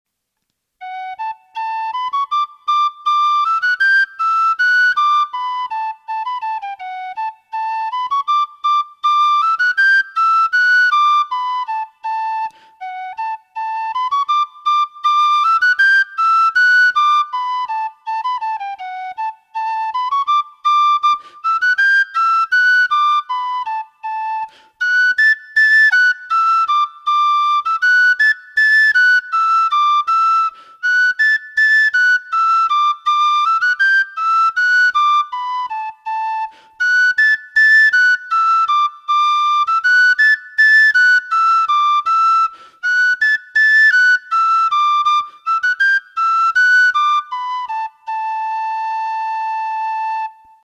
Traditional Irish Music - learning resources
Traditional Irish Music -- Learning Resources Spanish Lady, The (Polka) /